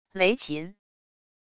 雷琴wav音频